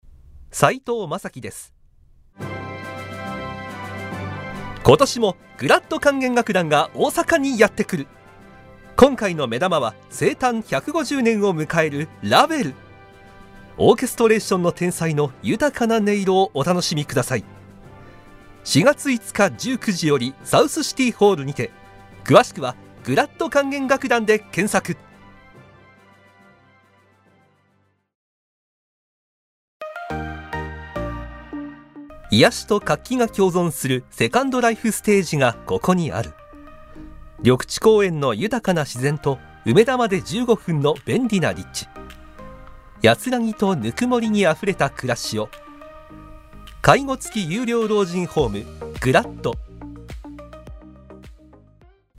ボイスサンプル
• 声ににじみ出る優しさ
• 音域：高～中音
• 声の特徴：さわやか、優しい
• CM